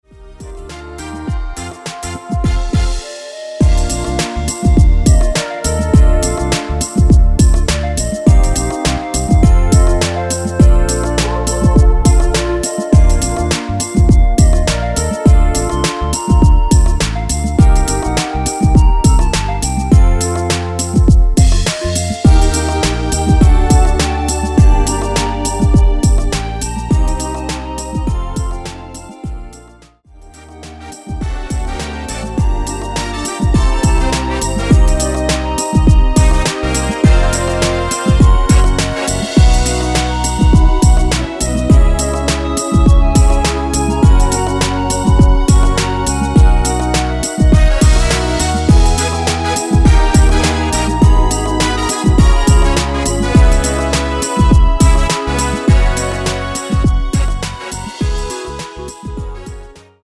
원키 멜로디 포함된 MR입니다.(미리듣기 확인)
Bb
앞부분30초, 뒷부분30초씩 편집해서 올려 드리고 있습니다.
중간에 음이 끈어지고 다시 나오는 이유는